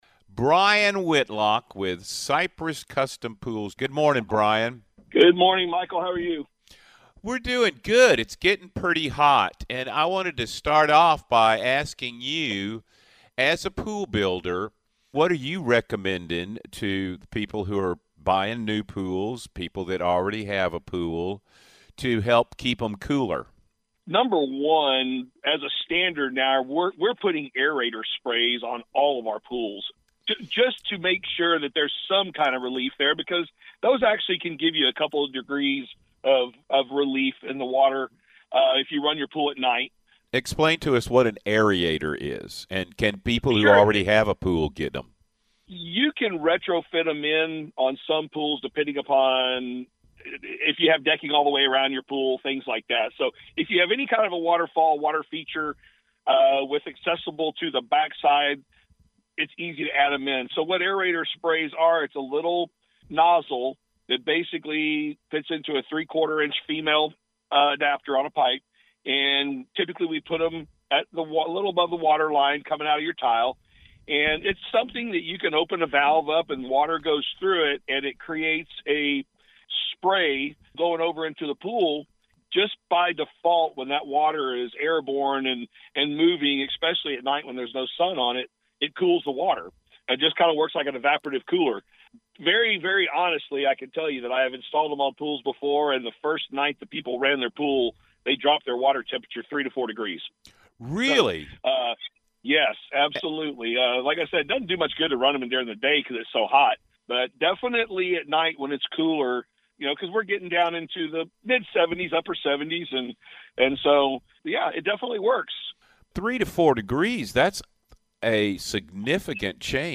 Listen to the story from the Backyard Bay Radio Show.